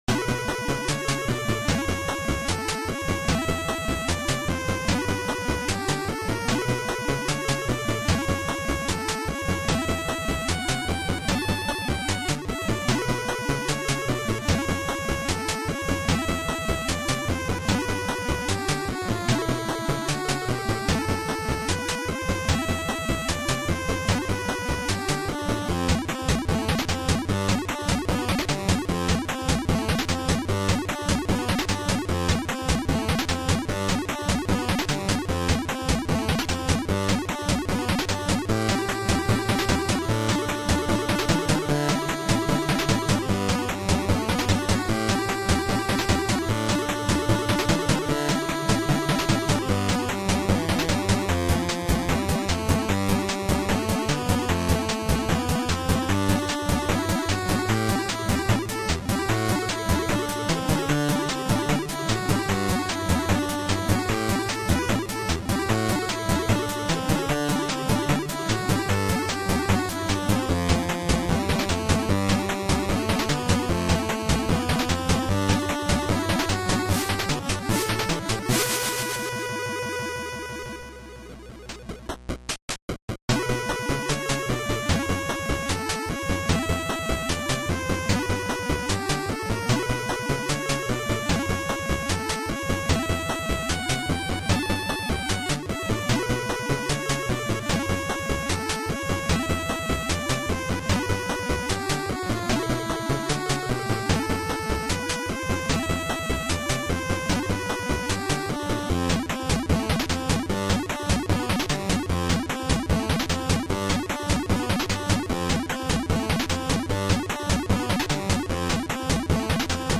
Video Game soundtracks